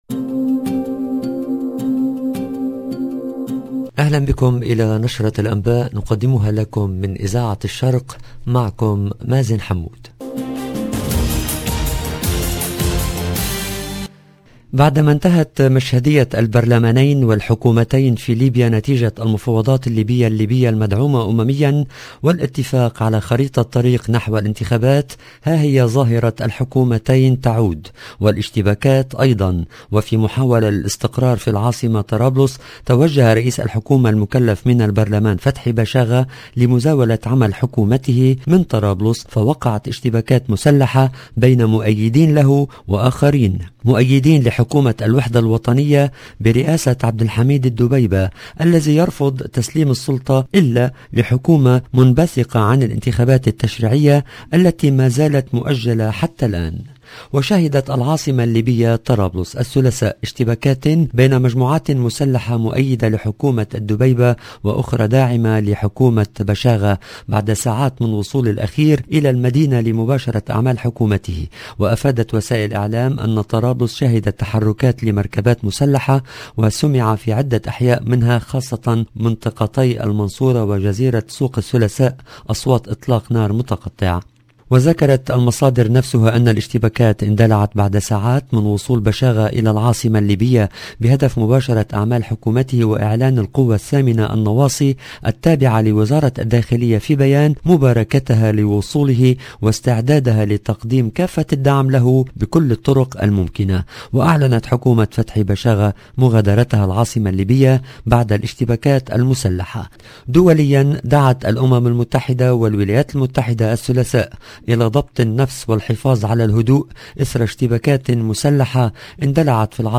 LE JOURNAL DU SOIR EN LANGUE ARABE DU 17/05/22